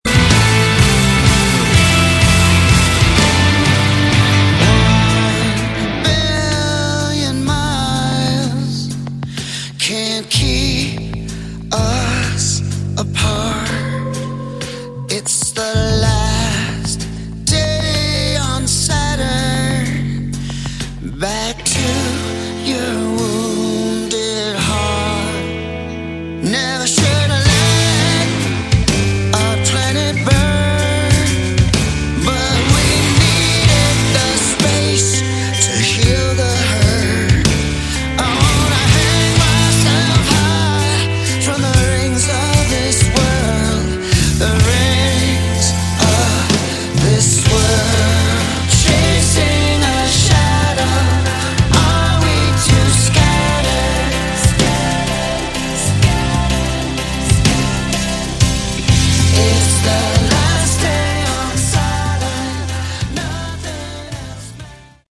Category: Melodic Rock
lead vocals, guitars, piano
drums, percussion, electronics
keyboards
electric and acoustic bass, vocals